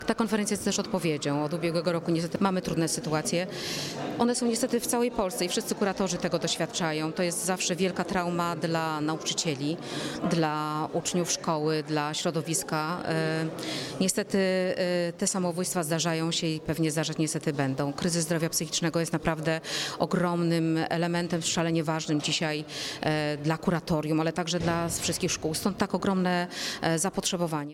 Wojewódzka Konferencja dla dyrektorów szkół i nauczycieli, poświęcona przeciwdziałaniu kryzysom emocjonalnym wśród najmłodszych, która odbyła się 11 czerwca jest odpowiedzią na kilka trudnych sytuacji, które miały miejsce na Dolnym Śląsku.
Mówi Dolnośląska Kurator Oświaty – Ewa Skrzywanek.